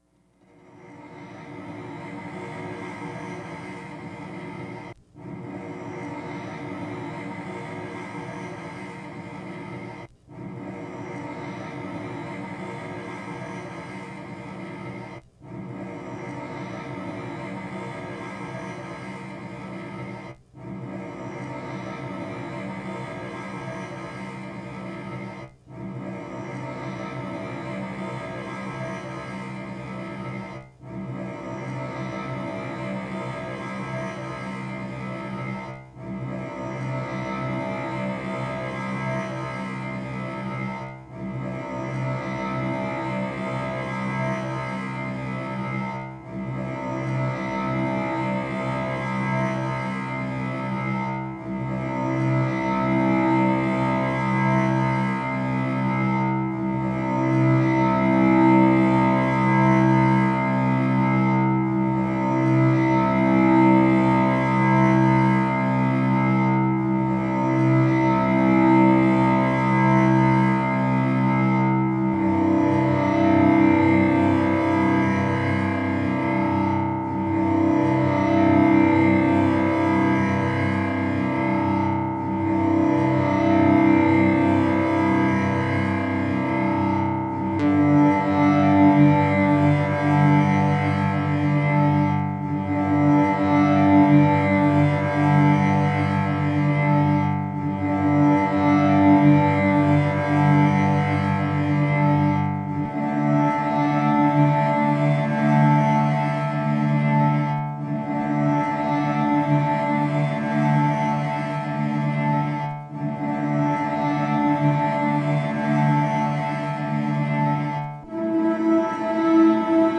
Processing: Stereo resonator (delay = 511) KS delays = 457, 458 (low C); raise amp and KS feedback to 1005;